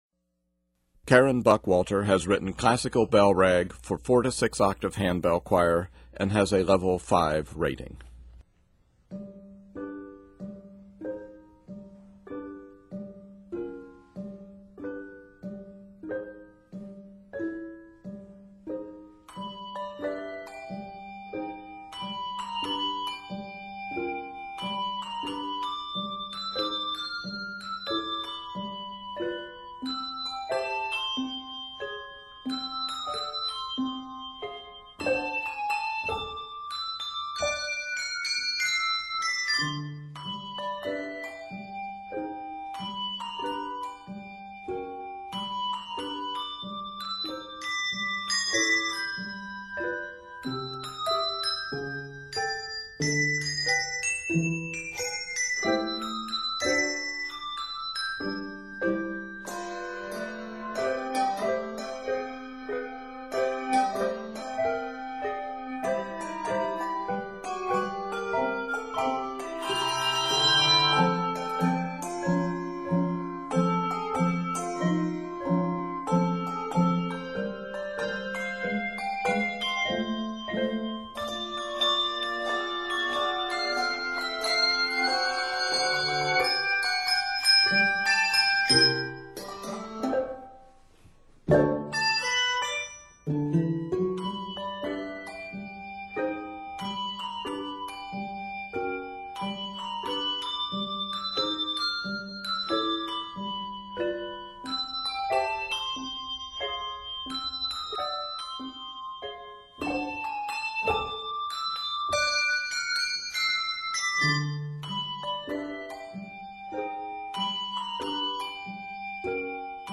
N/A Octaves: 4-6 Level